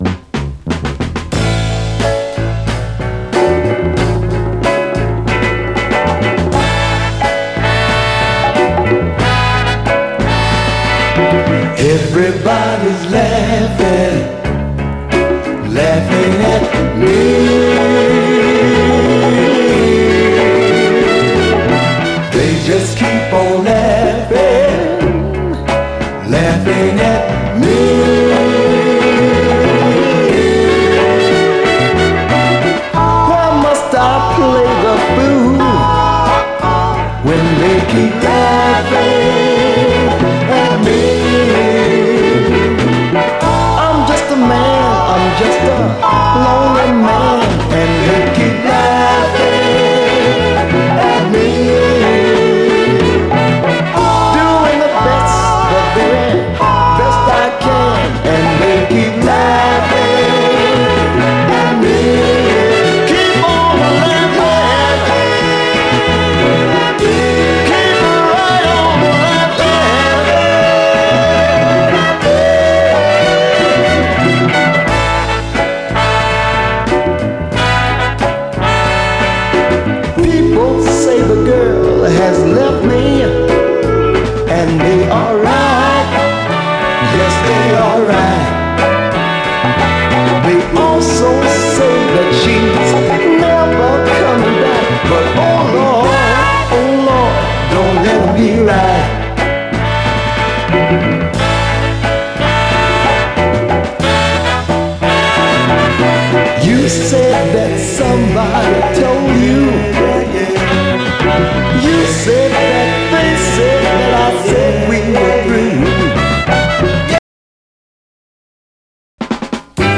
SOUL, 60's SOUL, 7INCH
黄金のシカゴ・ソウル！
泣きのスウィート・ソウル